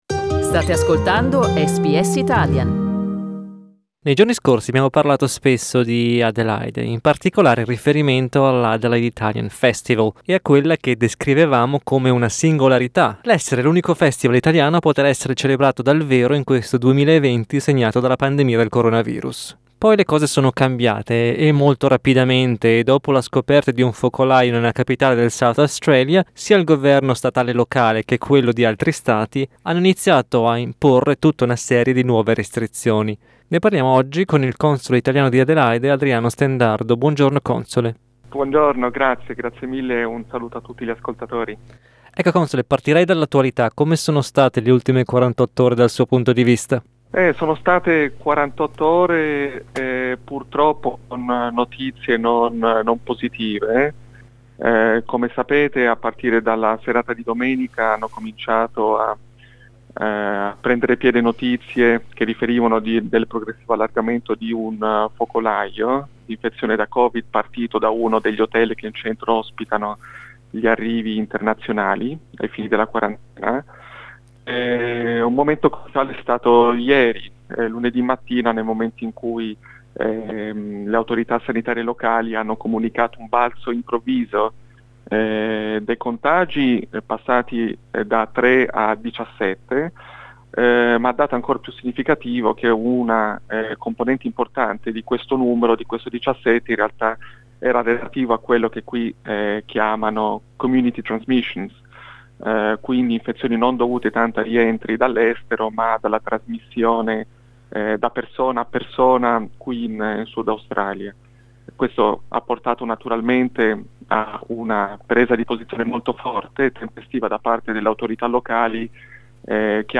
Ne abbiamo parlato con il Console d'Italia ad Adelaide, Adriano Stendardo, il quale ai nostri microfoni ha confermato che, a causa delle misure di contenimento del virus introdotte dal governo statale, molti eventi subiranno modifiche.